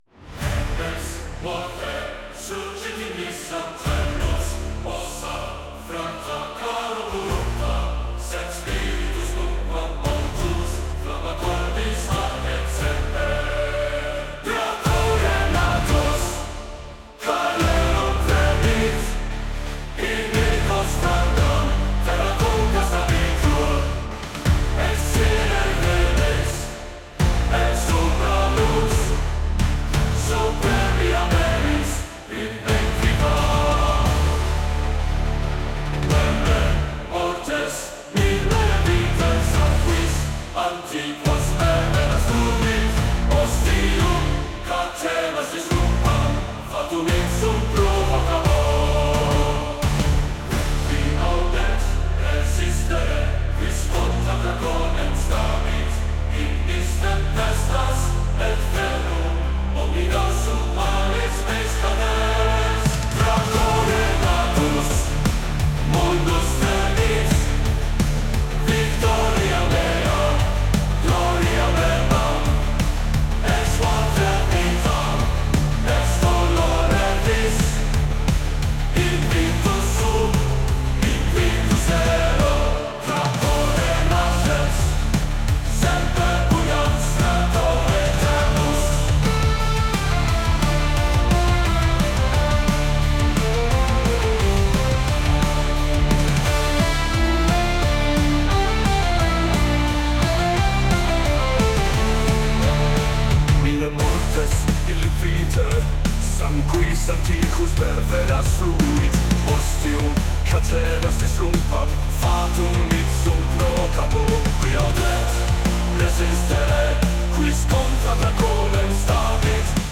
Created through use of AI